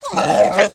Minecraft Version Minecraft Version snapshot Latest Release | Latest Snapshot snapshot / assets / minecraft / sounds / mob / wolf / puglin / death.ogg Compare With Compare With Latest Release | Latest Snapshot
death.ogg